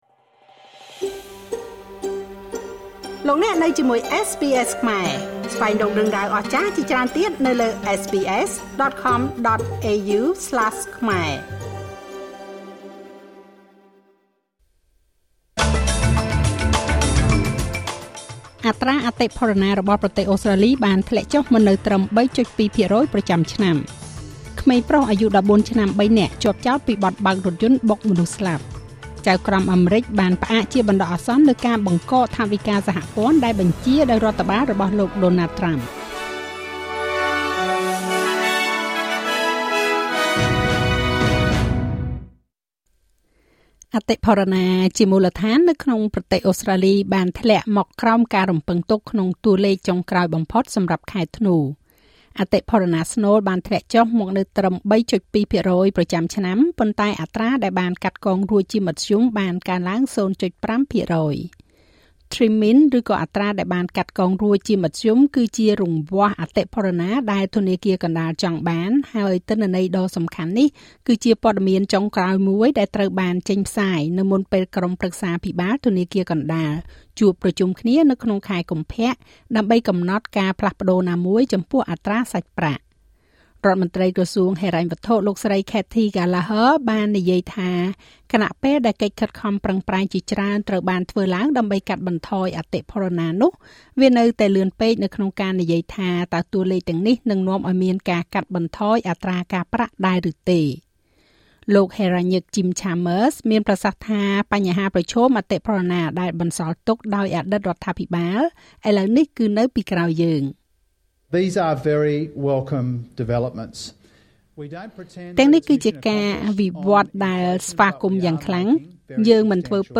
នាទីព័ត៌មានរបស់SBSខ្មែរ សម្រាប់ថ្ងៃពុធ ទី២៩ ខែមករា ឆ្នាំ២០២៥